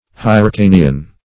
Search Result for " hyrcanian" : The Collaborative International Dictionary of English v.0.48: Hyrcanian \Hyr*ca"ni*an\, Hyrcan \Hyr"can\, a. Of or pertaining to Hyrcania, an ancient country or province of Asia, southeast of the Caspian (which was also called the Hyrcanian) Sea.